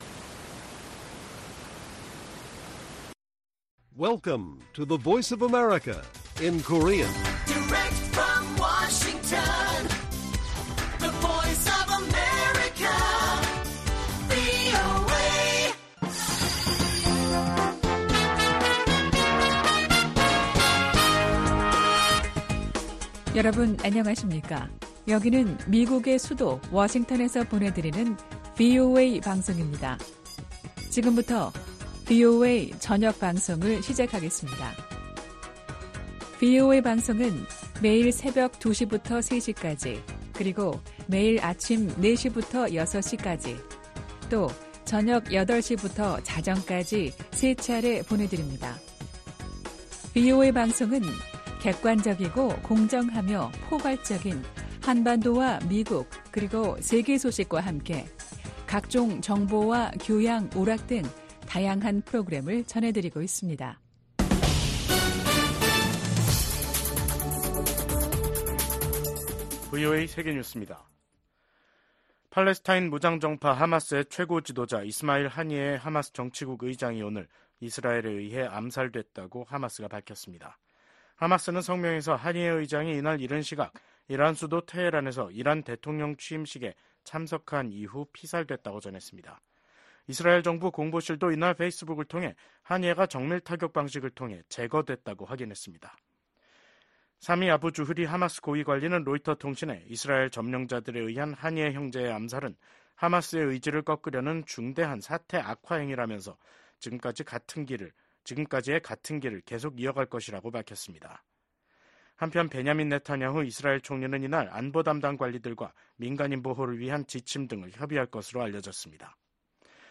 VOA 한국어 간판 뉴스 프로그램 '뉴스 투데이', 2024년 7월 31일 1부 방송입니다. 중국과 러시아가 북한에 대한 영향력을 놓고 서로 경쟁하고 있다고 미국 국무부 부장관이 평가했습니다. 최근 몇 년간 중국, 러시아, 이란, 북한간 협력이 심화돼 미국이 냉전 종식 이후 가장 심각한 위협에 직면했다고 미국 의회 산하 기구가 평가했습니다.